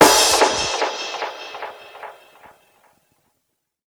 INSNAREFX1-R.wav